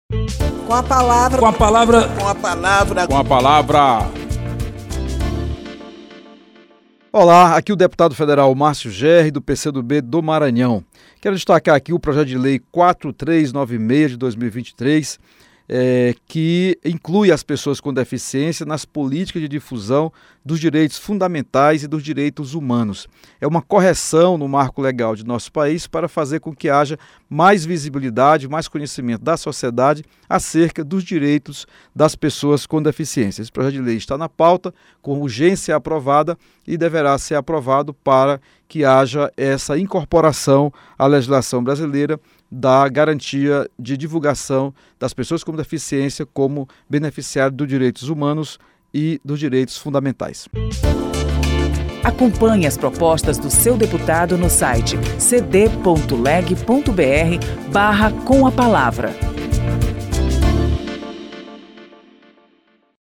O deputado Márcio Jerry (PCdoB-MA) defendeu a aprovação do projeto que inclui as pessoas com deficiência nas políticas públicas de difusão dos direitos fundamentais e dos direitos humanos.
Espaço aberto para que cada parlamentar apresente aos ouvintes suas propostas legislativas